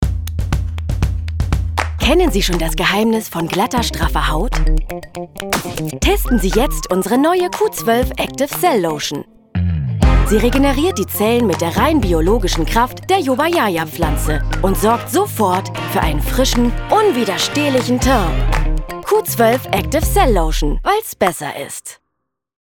sehr variabel, hell, fein, zart, dunkel, sonor, souverän, plakativ, markant
Commercial (Werbung)